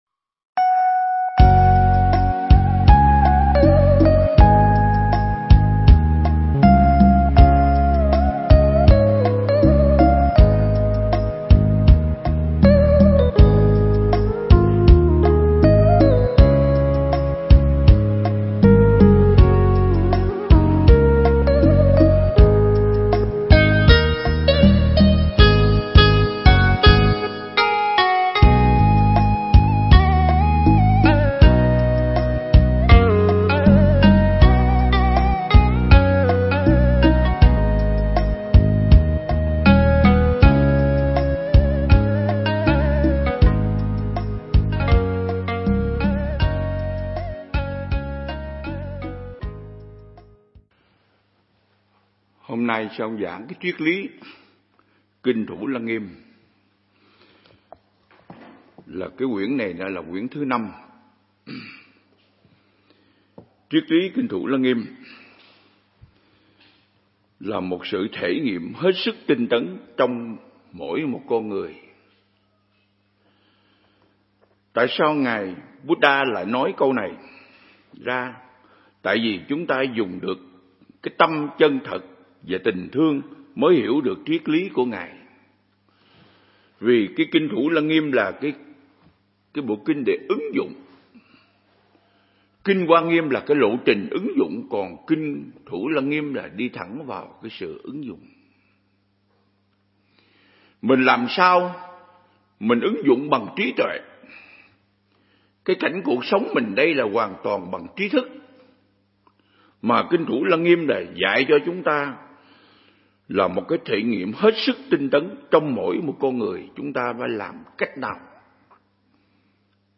Mp3 Pháp Thoại Triết Lý Thủ Lăng Nghiêm Phần 1